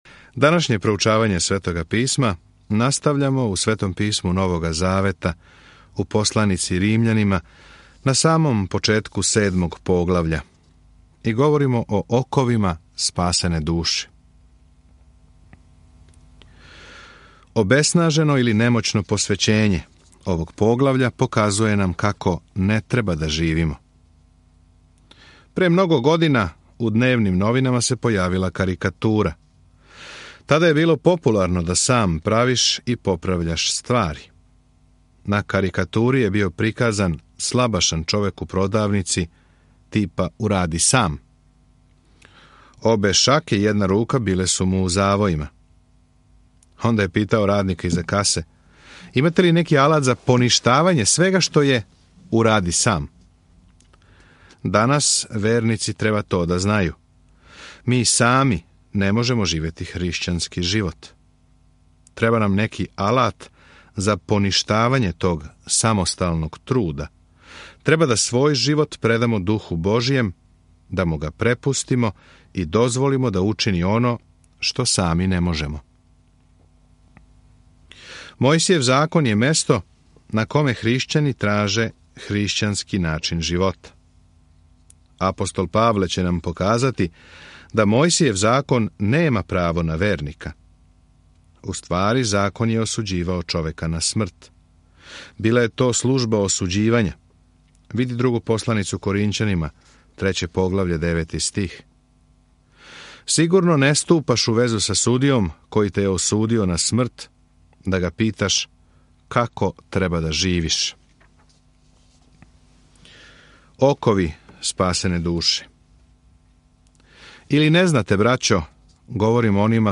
圣经 罗马书 7:1-10 计划天： 15 开始此计划 计划天： 17 读经计划介绍 Писмо Римљанима одговара на питање „које су добре вести?“ И како неко може да верује, да се спасе, да се ослободи смрти и да расте у вери. Свакодневно путујте кроз посланицу Римљанима док слушате аудио студију и читате одабране стихове из Божје речи.